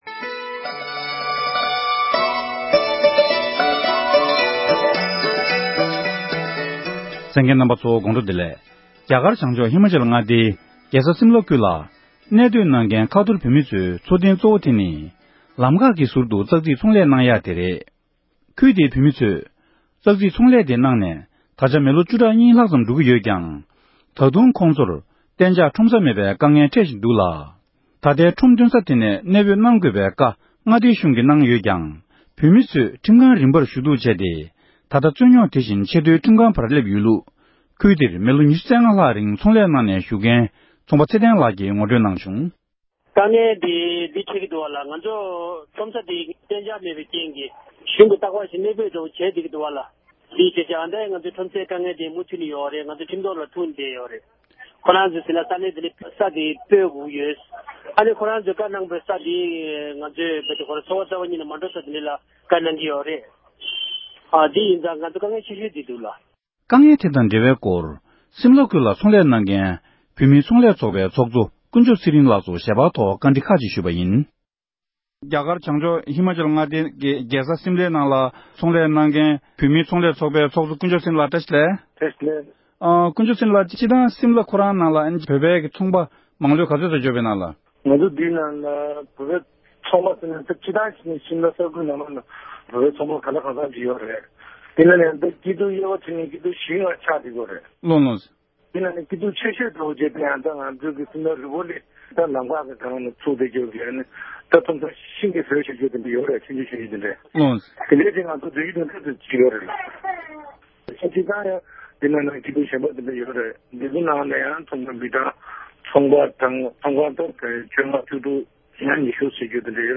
བཀའ་འདྲི་ཞུས་པ་ཞིག